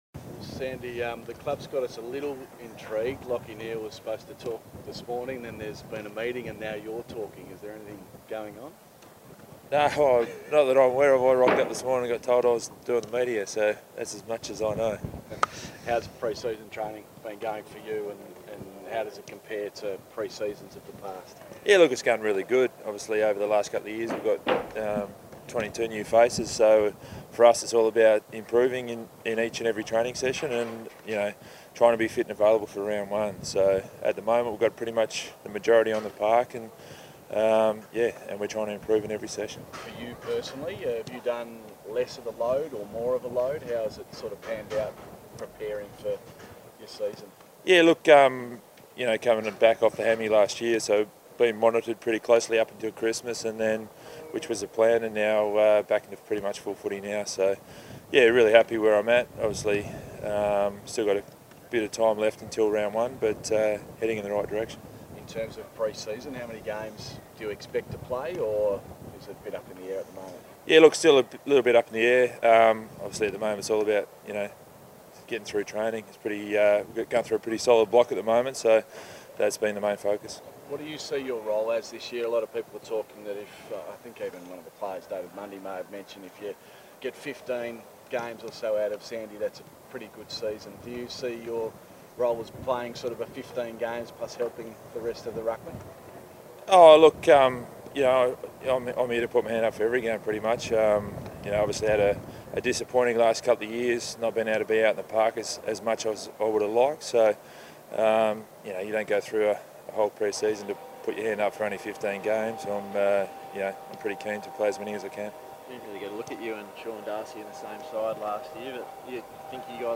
Aaron Sandilands chats to the media about his progress this pre-season.